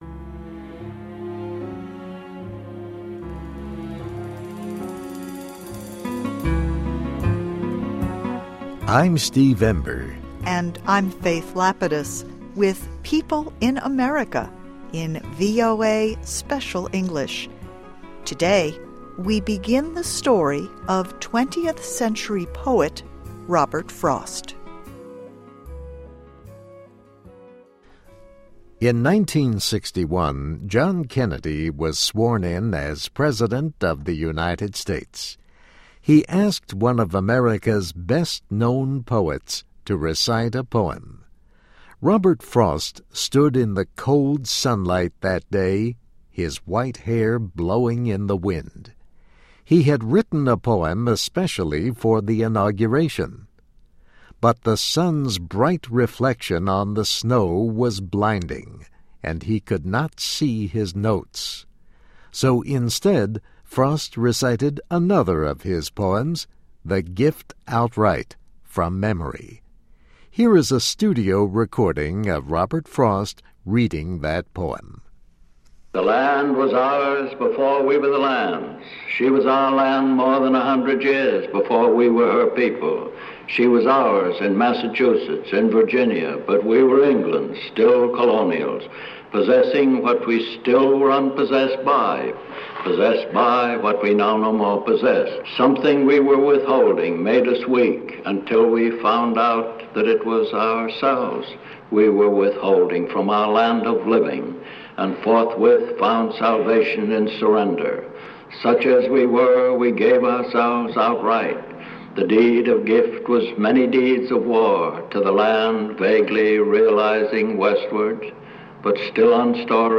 Listen and Read Along - Text with Audio - For ESL Students - For Learning English
Here is a studio recording of Robert Frost reading that poem.